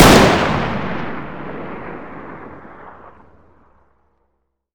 m82_distance_fire1.wav